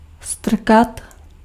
Ääntäminen
IPA : /pʊʃ/ US : IPA : [pʊʃ]